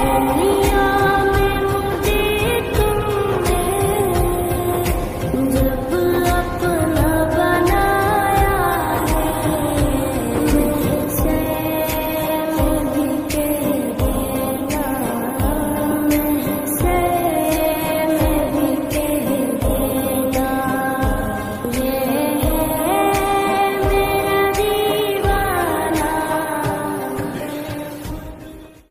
Category: Naat Ringtones